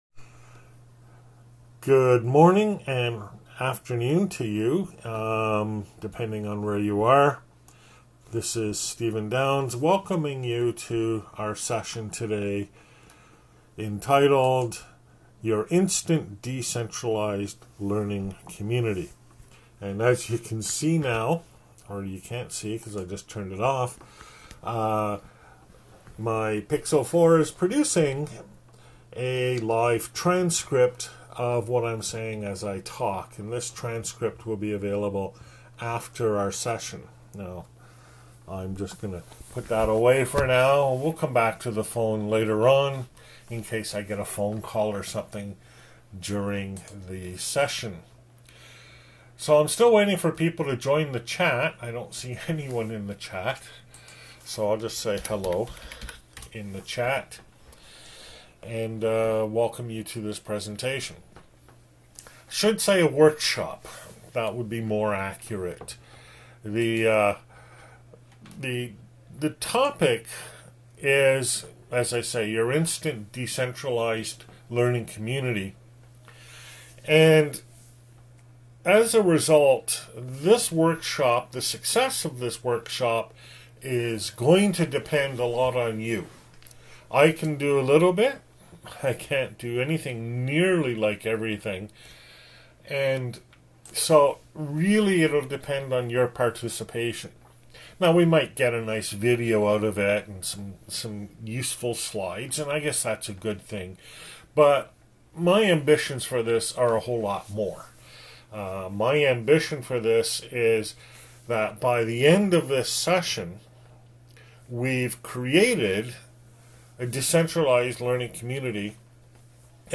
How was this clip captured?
(Old style) [ Slides ] [ PDF ] [ Audio ] [ Video ] (New Style) [ PDF ] [] INTED, Online, via Zoom, Workshop, Mar 08, 2021.